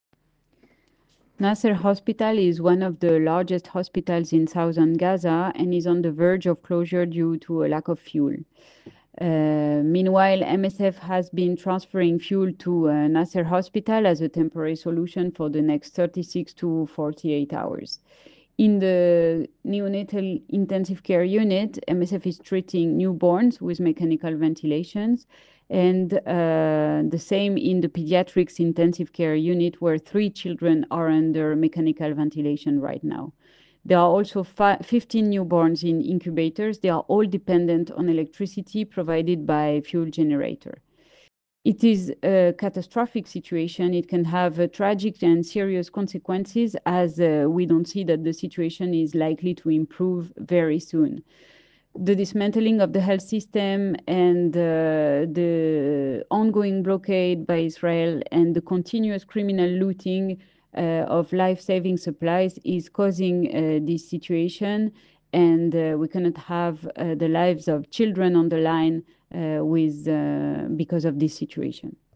Voice note